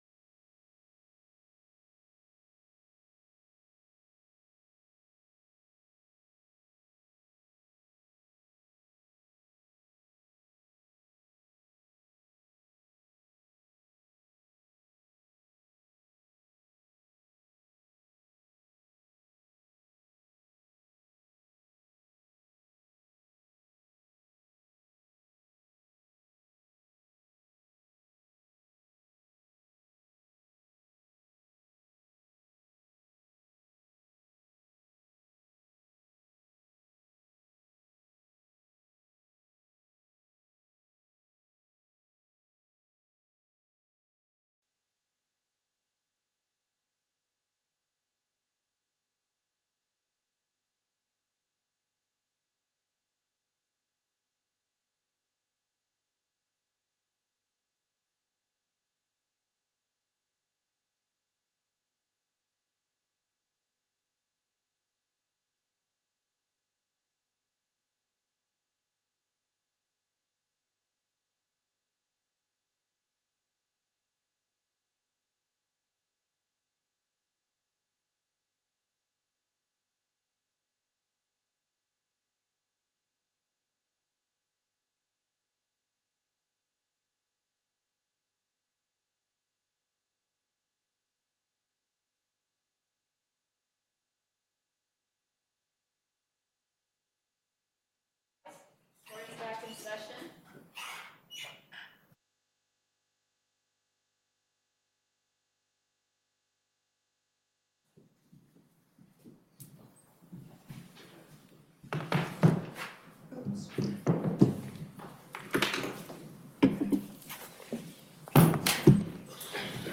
Before the En Banc Court, Chief Justice Herndon presiding Appearances